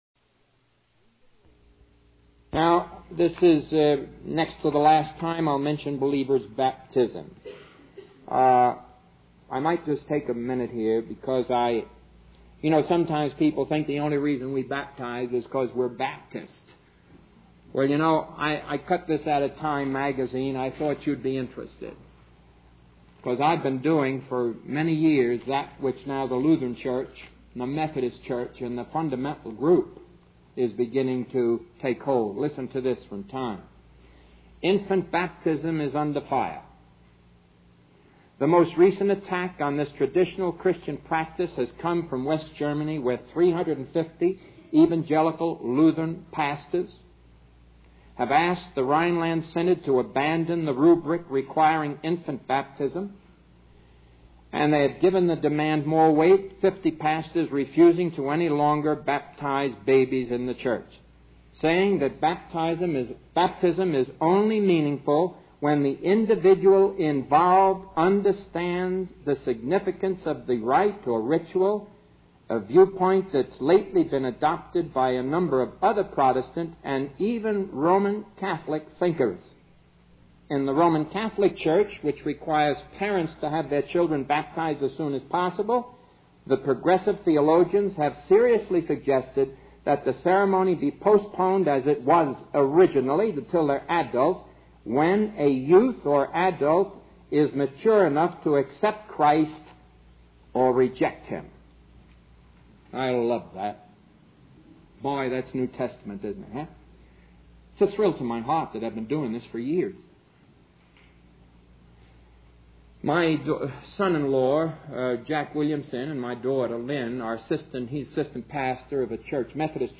In this sermon, the speaker addresses the confusion and moral decay that is prevalent in society today.